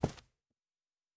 Footstep Carpet Running 1_01.wav